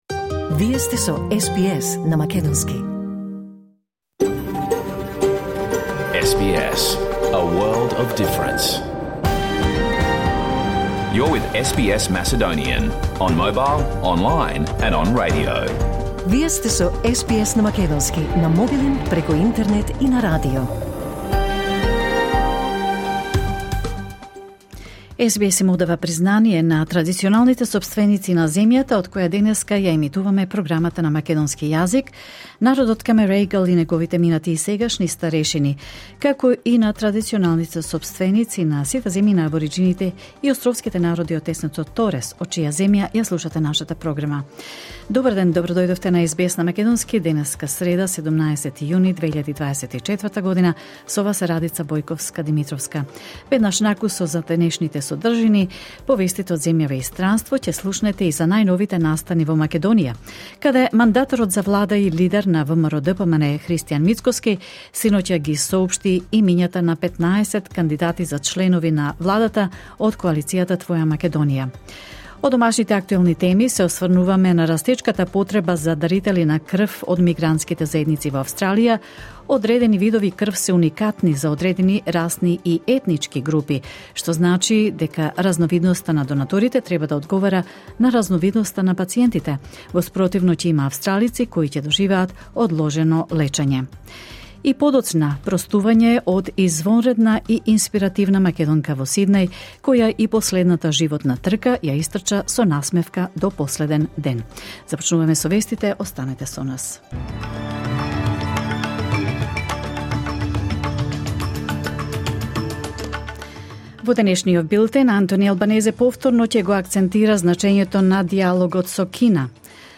SBS Macedonian Program Live on Air 17 June 2024